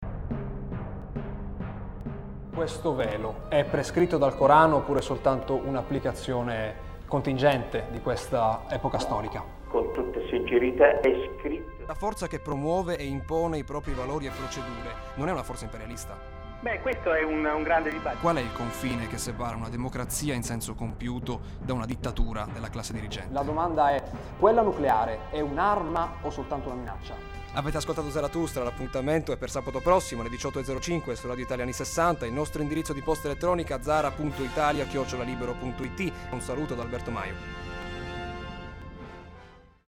Da oggi su Radio Italia anni '60 Emilia Romagna va in onda il promo di Zarathustra, realizzato con pezzettini microscopici delle primissime puntate.